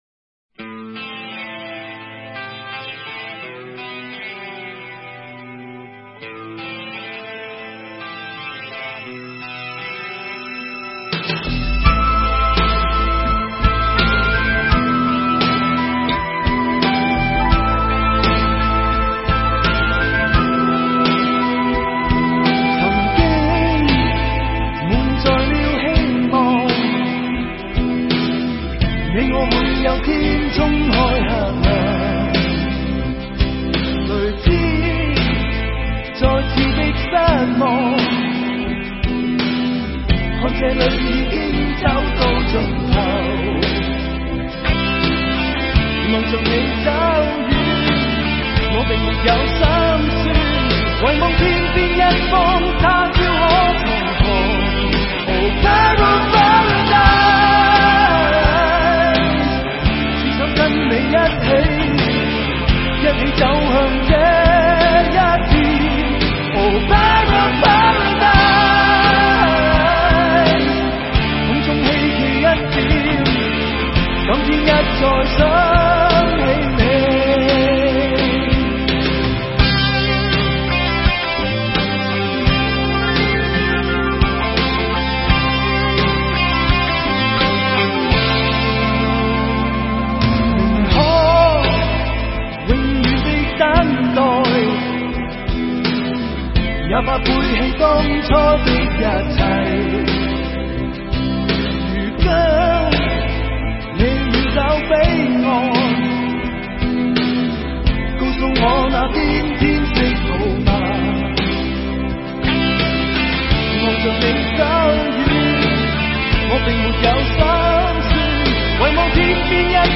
主唱